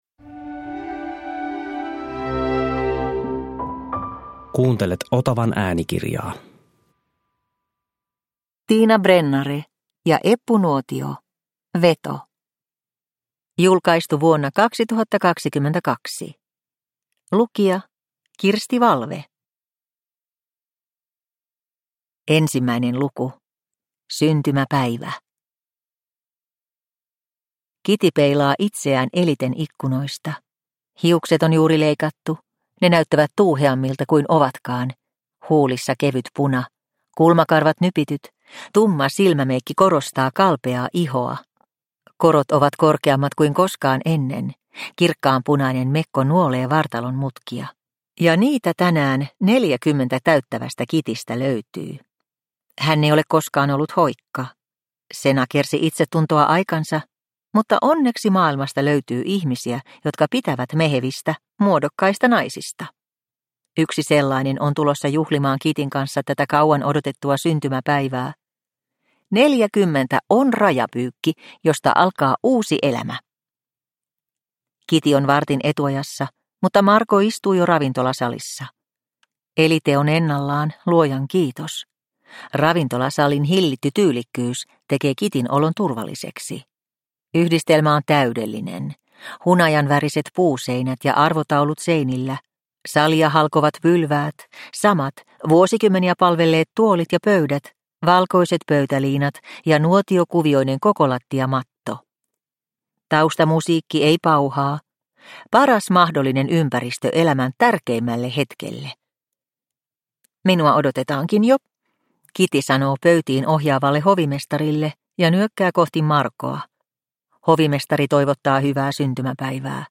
Veto – Ljudbok – Laddas ner